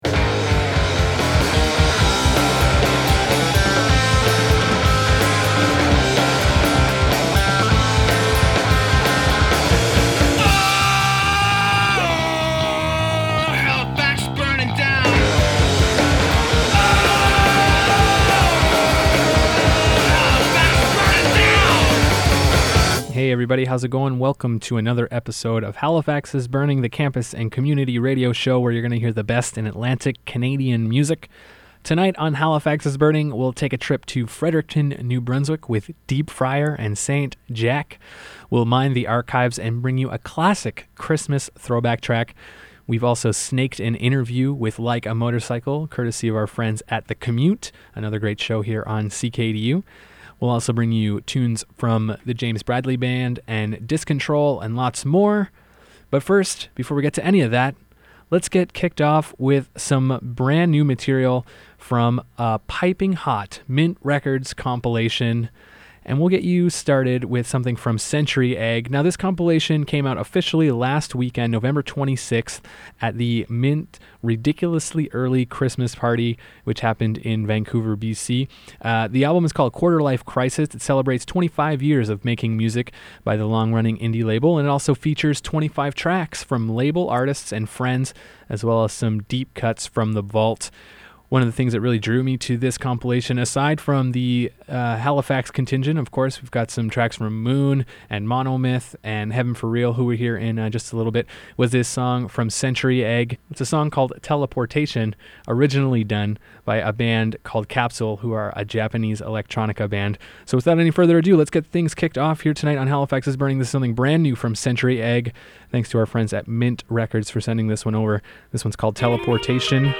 Features an interview with Like A Motorcycle + the best independent East Coast music